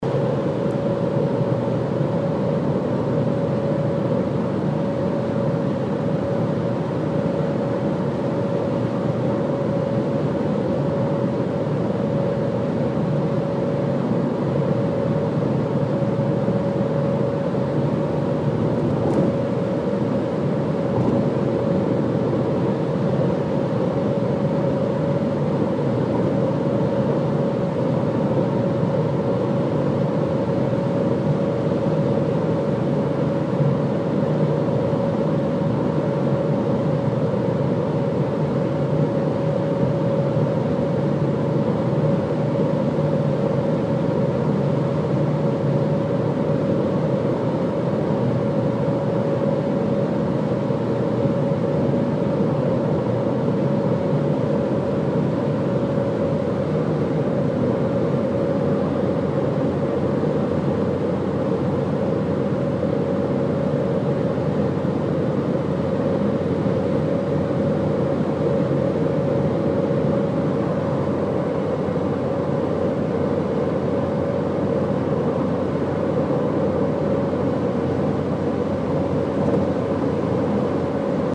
Ab 135 km/h pfeift es aus dem Kofferraum - Jetzt mit Ton!
Hab mich mal mit einem Equalizer herumgespielt und damit herausgefunden, dass das Pfeifen bei 500Hz liegt (bei ~135 km/h). Dreh ich den Regler bei 500Hz runter, ist das Pfeifen komplett weg.
audi_pfeifen.mp3